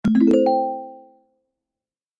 dialog-question.ogg